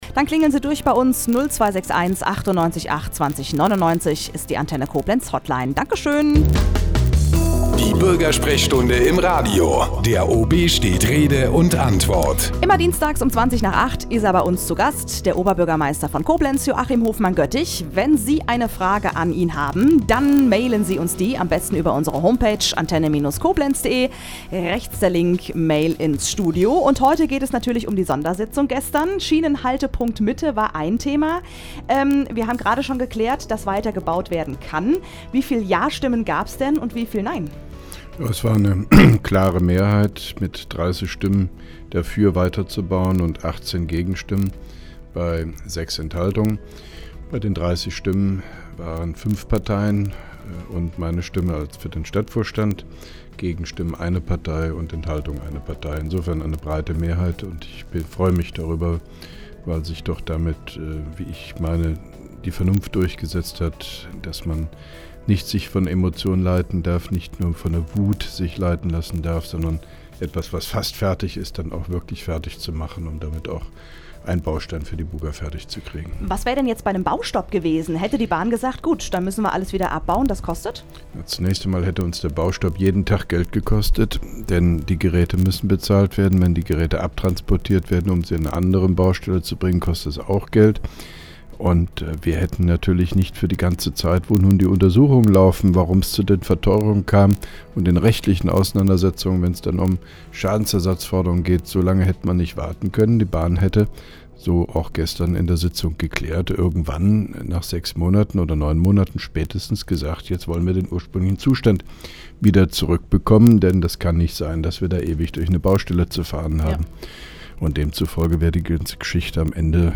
(2) Koblenzer Radio-Bürgersprechstunde mit OB Hofmann-Göttig 18.01.2011